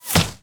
bullet_impact_snow_04.wav